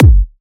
Smooth Kick Single Shot G Key 733.wav
Royality free kick single hit tuned to the G note. Loudest frequency: 444Hz
smooth-kick-single-shot-g-key-733-mZK.mp3